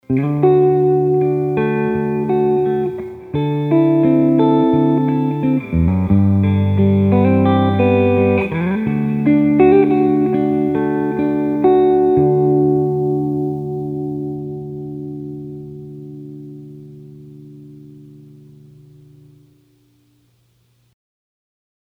Clean – Both Pickups
Clean clips were played in the Clean channel of my Fender Hot Rod Deluxe, while the dirty clips were played in the Drive channel.
clean-both.mp3